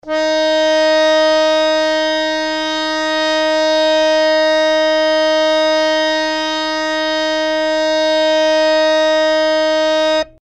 harmonium